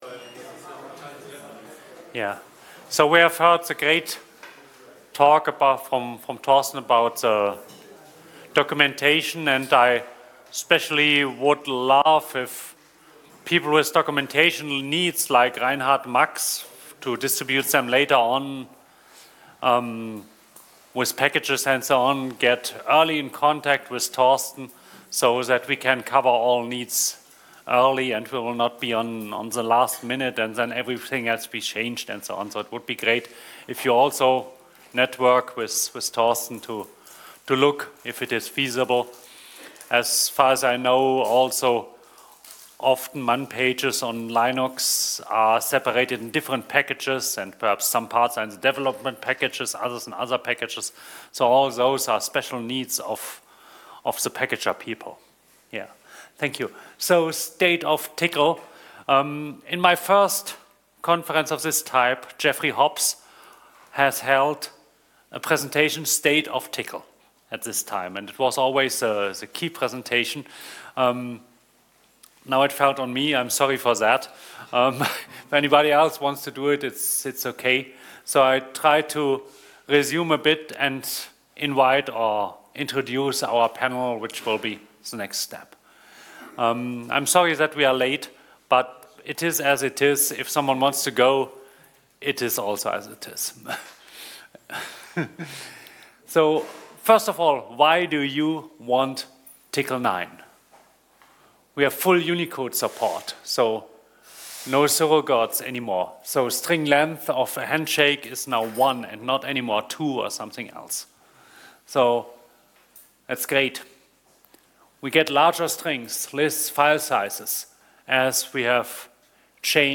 Panel and discussion
OpenACS and Tcl/Tk Conference 2024